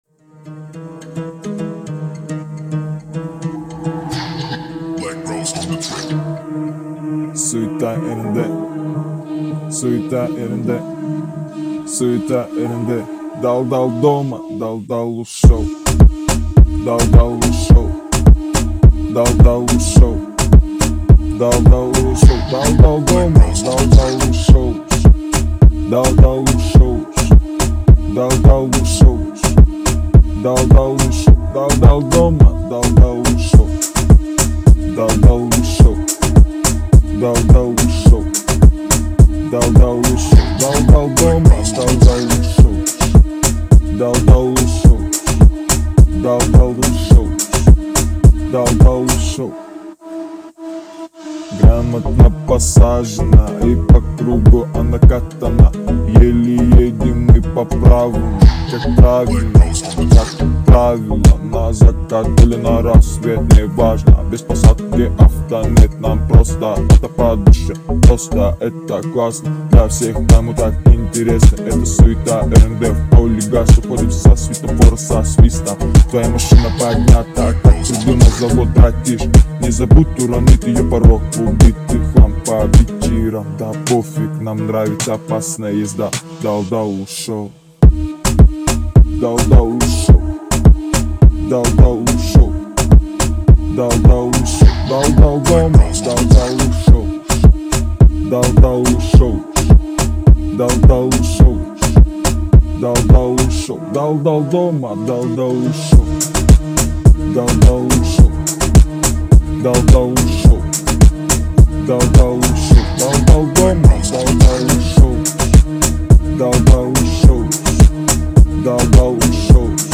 это энергичная и зажигательная песня в жанре поп